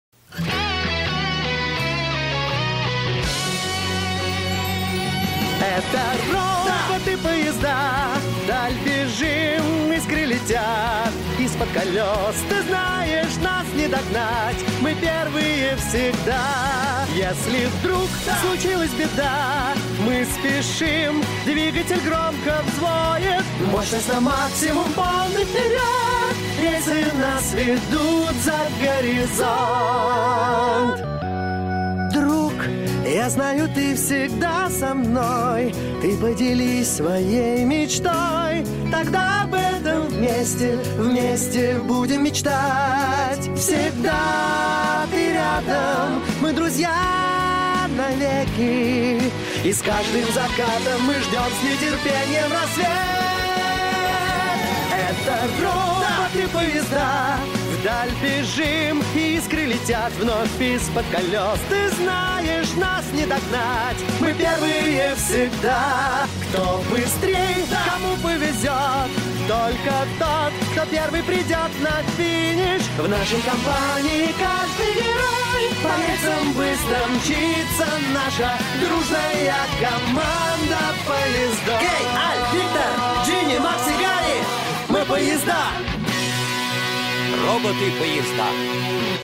• Категория: Детские песни
🎶 Детские песни / Песни из мультфильмов